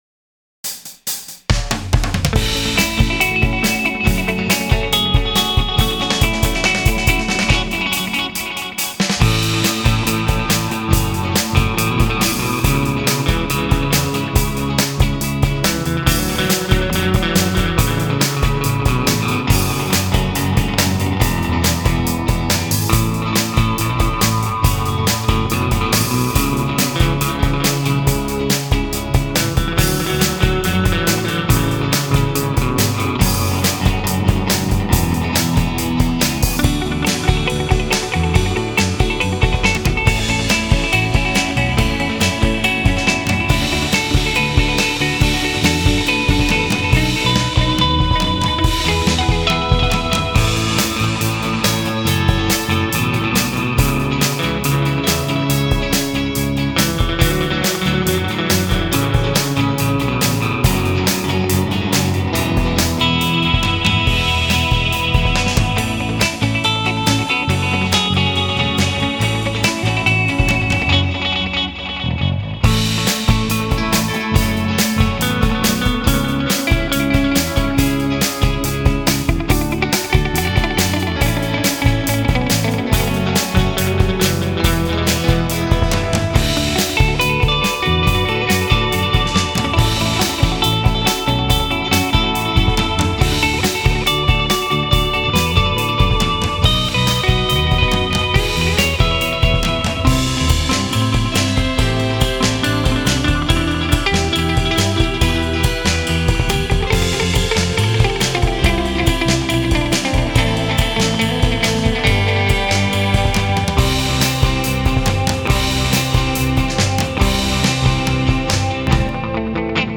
Genre: surfrock, rocknroll.